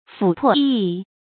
斧破斨缺 注音： ㄈㄨˇ ㄆㄛˋ ㄑㄧㄤ ㄑㄩㄝ 讀音讀法： 意思解釋： 謂武器破損殘缺。